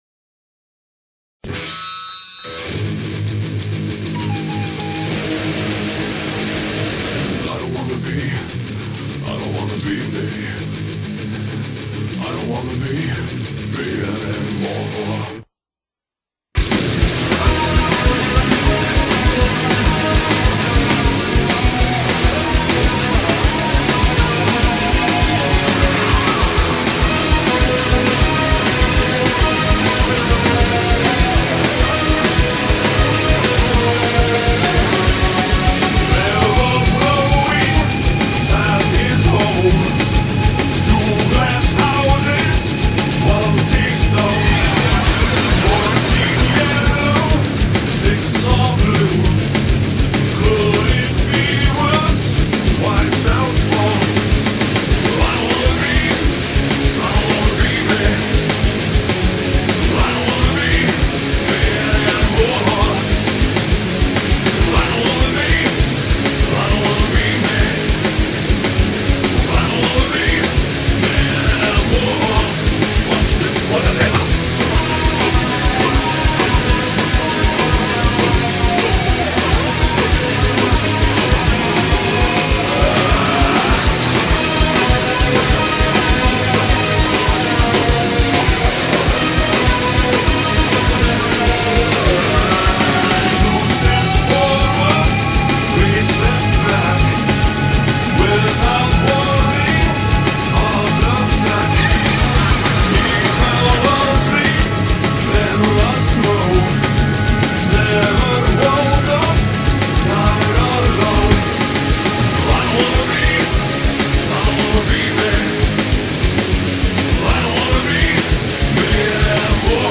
alternative metal